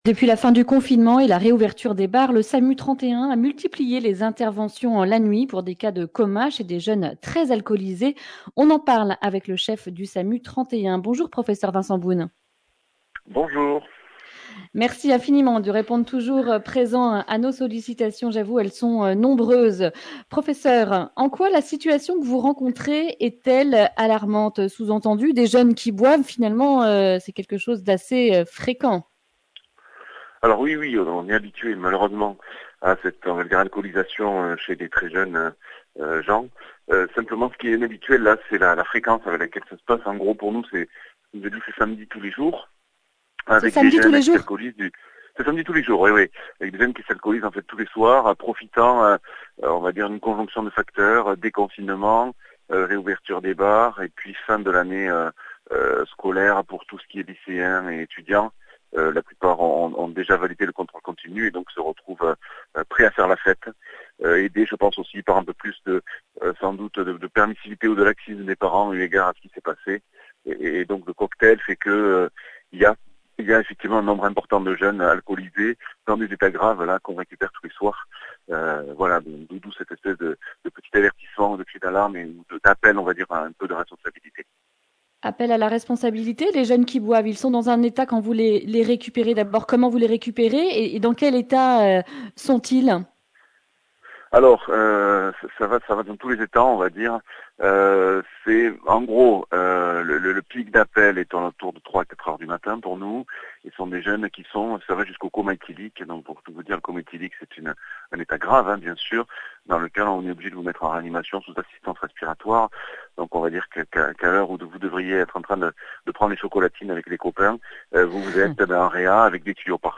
mercredi 17 juin 2020 Le grand entretien Durée 11 min